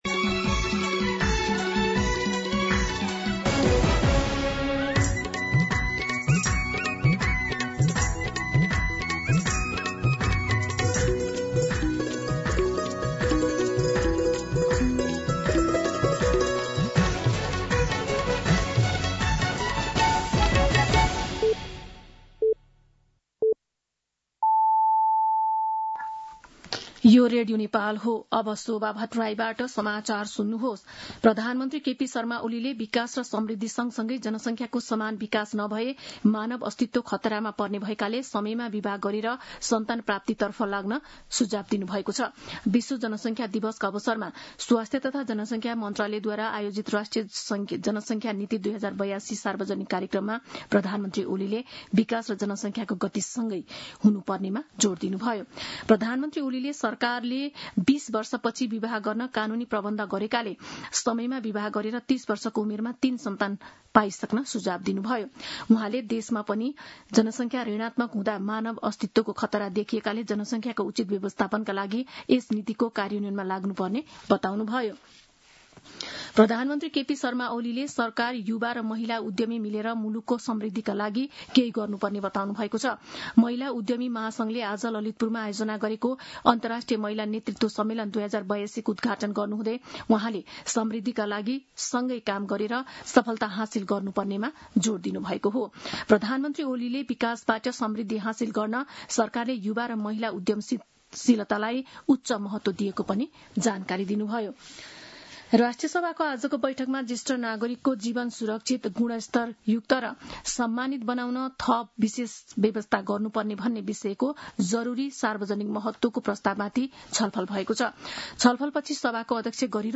दिउँसो ४ बजेको नेपाली समाचार : २७ असार , २०८२
4-pm-Nepali-News.mp3